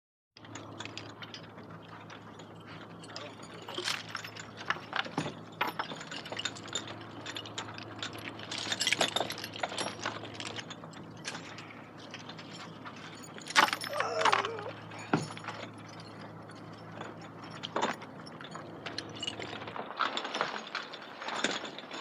На этой странице собраны звуки кандалов: звон цепей, скрежет металла, тяжелые шаги в оковах.
Звук кандалов при движении рук или ног